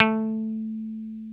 Index of /90_sSampleCDs/Roland L-CD701/GTR_Dan Electro/GTR_Dan-O 6 Str